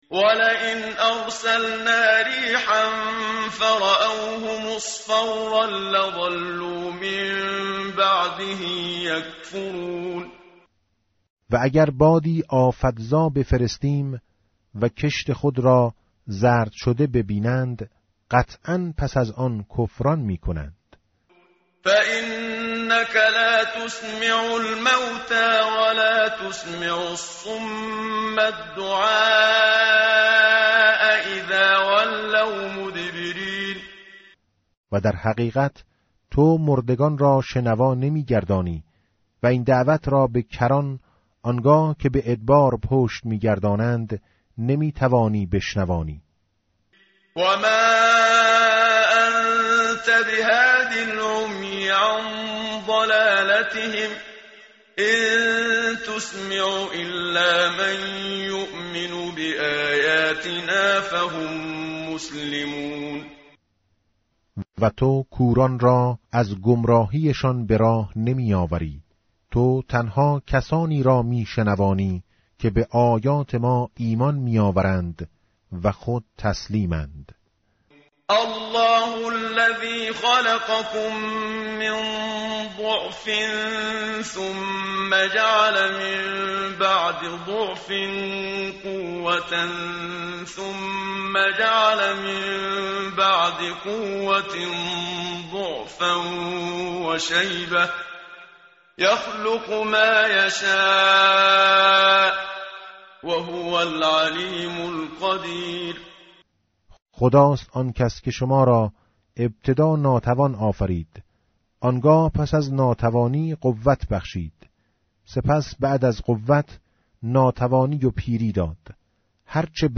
tartil_menshavi va tarjome_Page_410.mp3